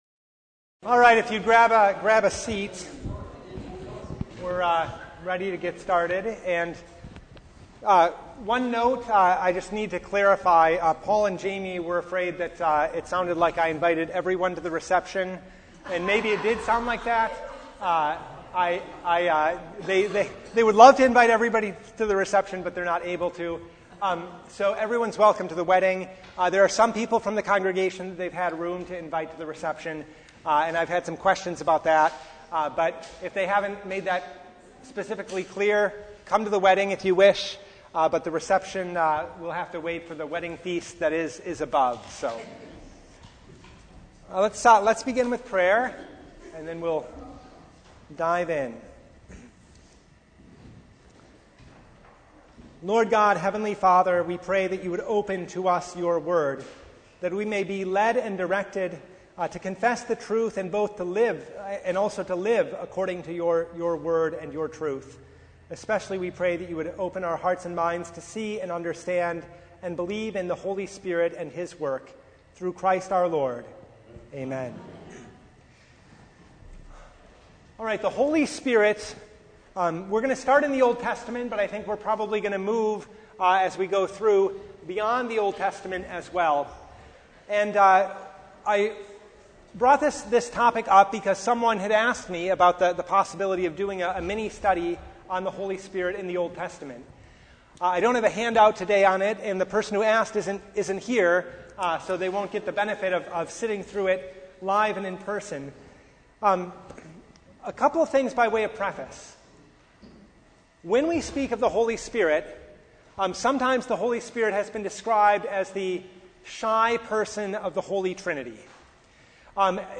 Passage: Genesis 1:1-2 Service Type: Bible Hour Topics